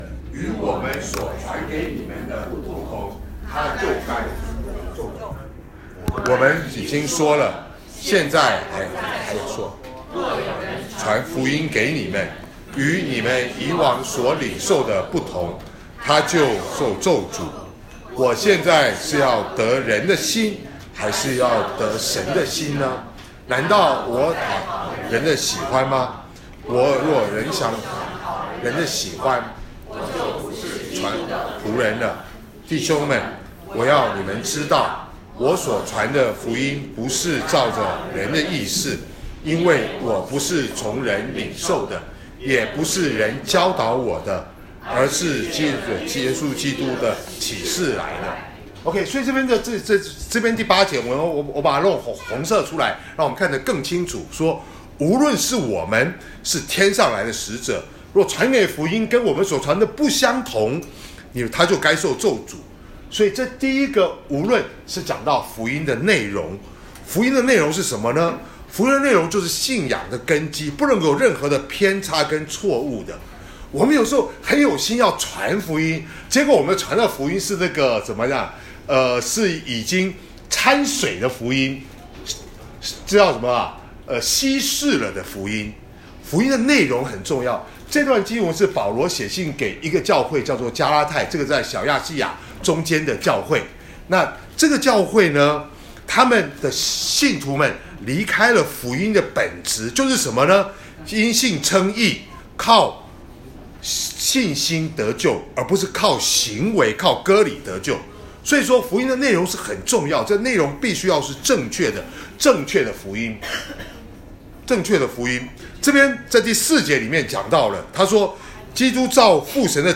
2019年4月28日主日讲道：传福音（一）